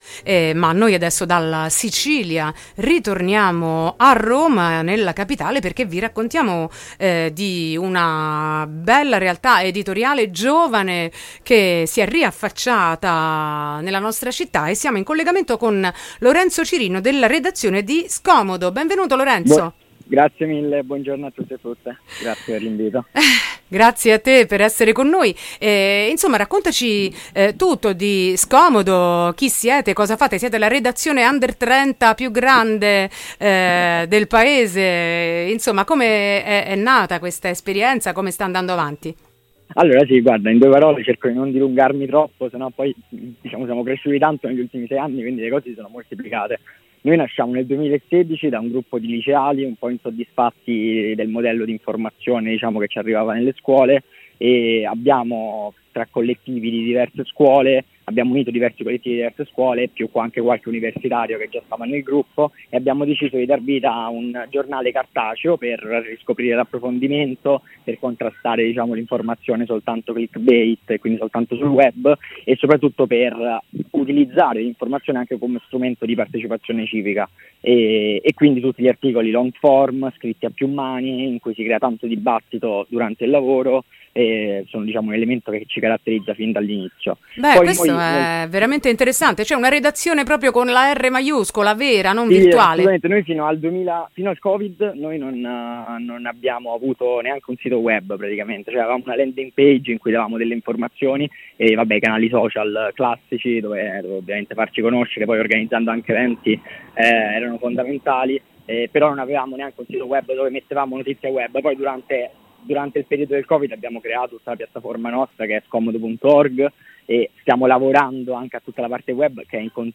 intervista-scomodo.mp3